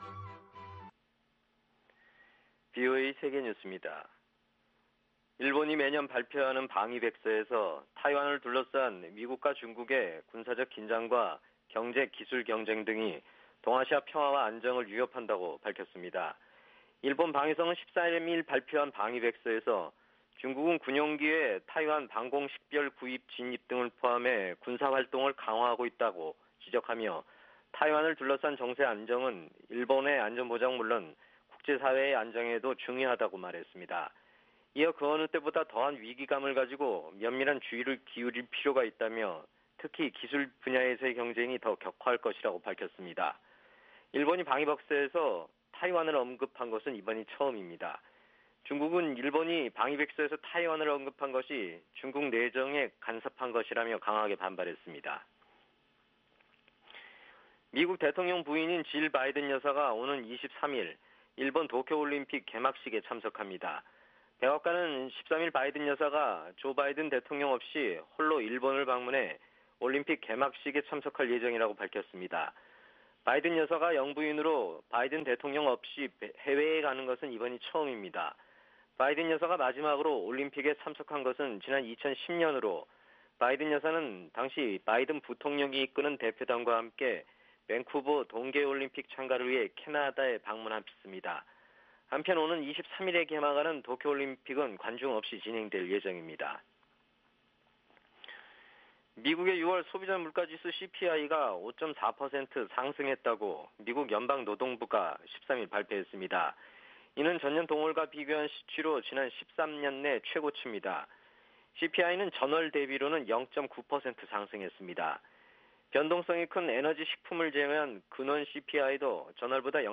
VOA 한국어 아침 뉴스 프로그램 '워싱턴 뉴스 광장' 2021년 7월 14일 방송입니다. 유엔은 북한 주민 42%가 영양부족에 시달리고 있으며, 세계에서 4번째로 높은 수준이라고 밝혔습니다.